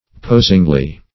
posingly - definition of posingly - synonyms, pronunciation, spelling from Free Dictionary Search Result for " posingly" : The Collaborative International Dictionary of English v.0.48: Posingly \Pos"ing*ly\, adv. So as to pose or puzzle.